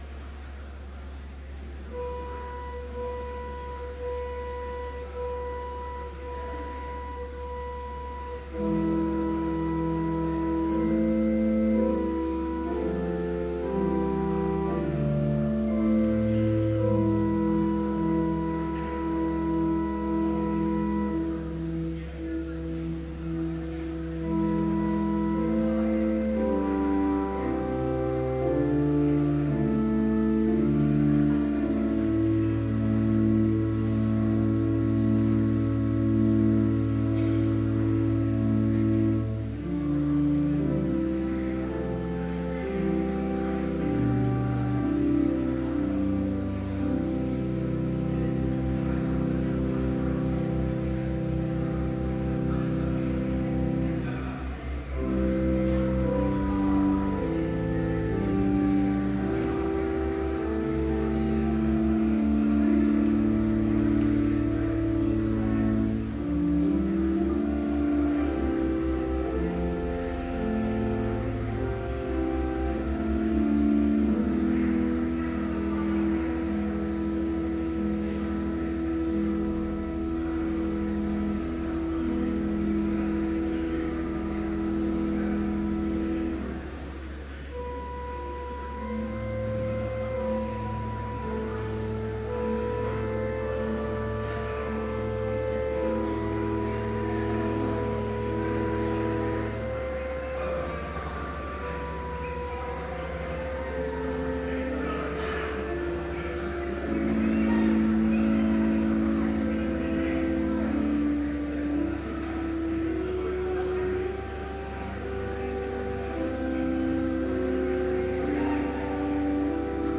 Complete service audio for Chapel - March 19, 2013